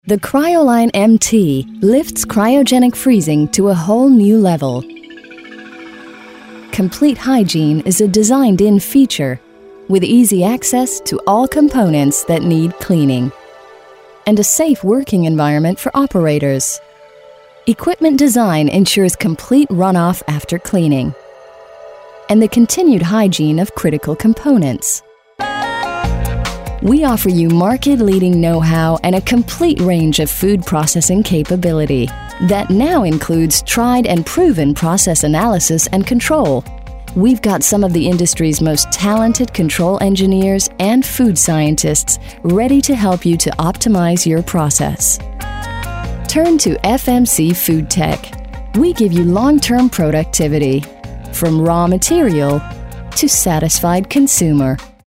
US